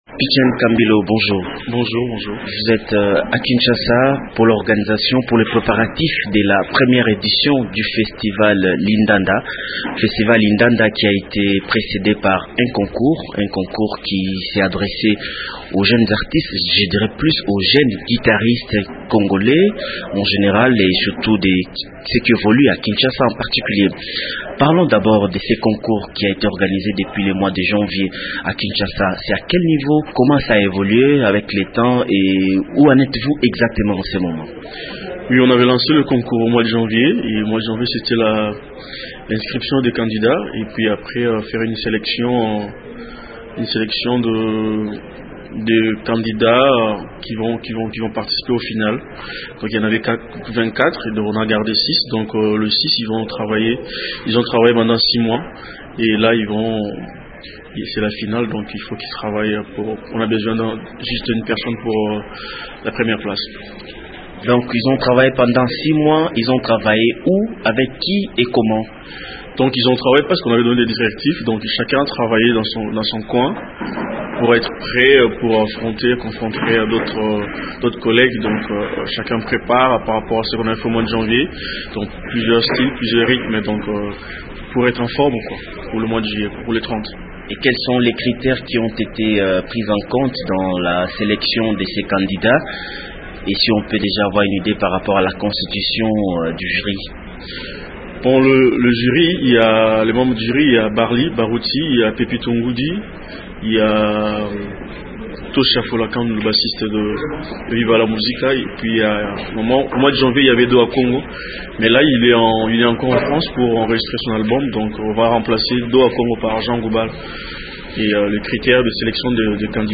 artiste musicien congolais